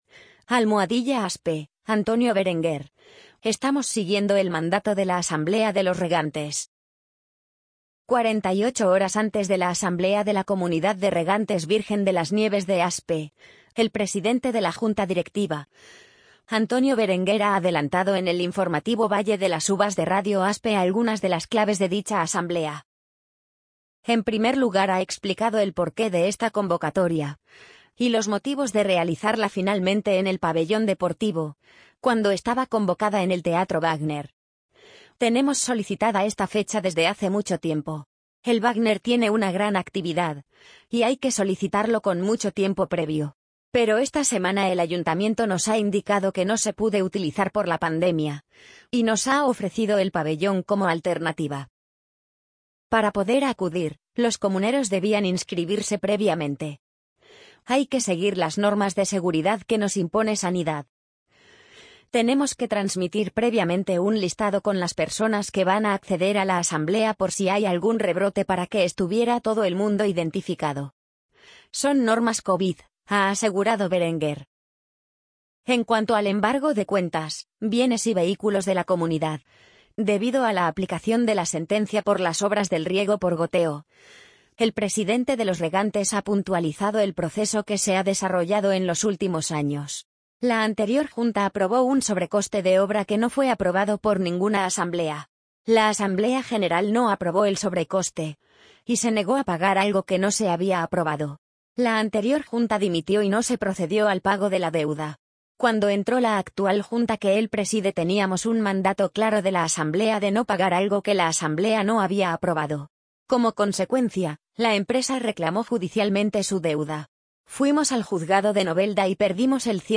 amazon_polly_46947.mp3